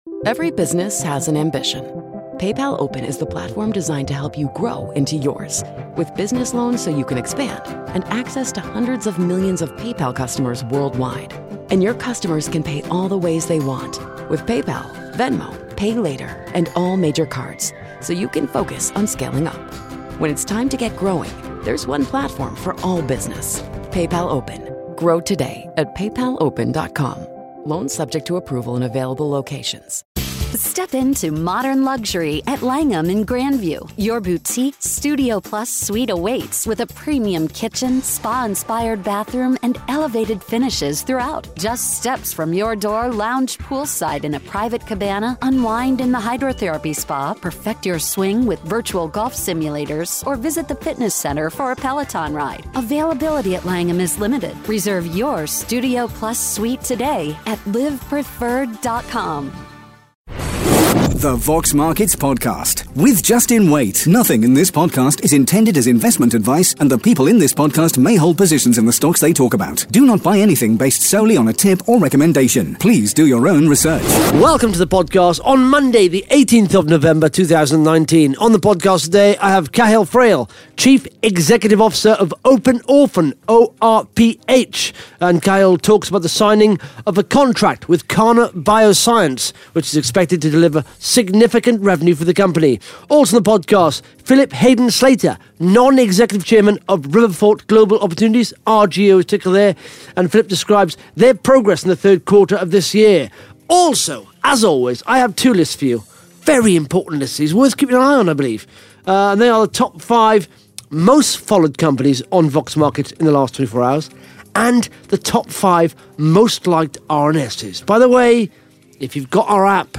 (Interview starts at 10 minutes 6 seconds) Plus the Top 5 Most Followed Companies & the Top 5 Most Liked RNS’s on Vox Markets in the last 24 hours.